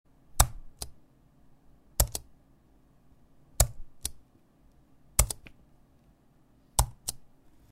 Звуки печатания